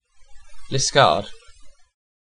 Liskeard (/lɪˈskɑːrd/
lih-SKARD; Cornish: Lyskerrys[2]) is an ancient stannary and market town in south-east Cornwall, England, United Kingdom.
En-uk-Liskeard.ogg.mp3